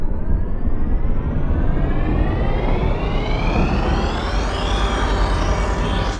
plasmaCannonPowerup.wav